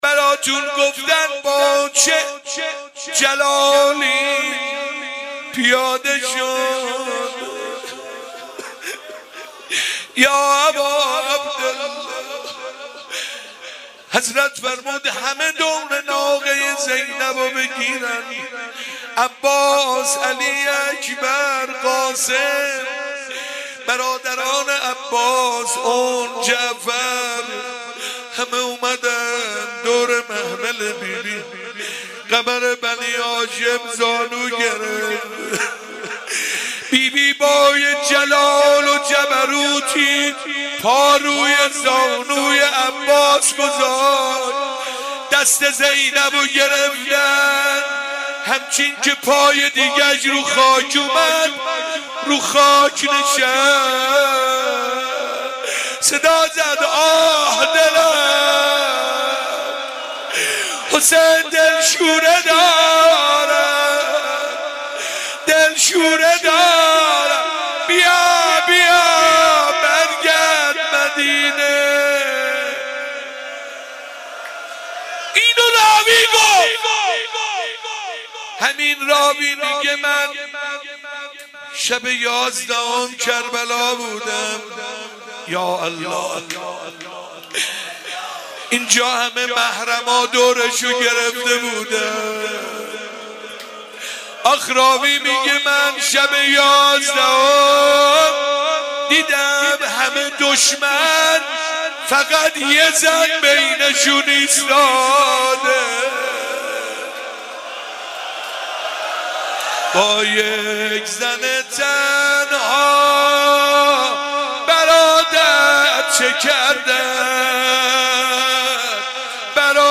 روضه شب دوم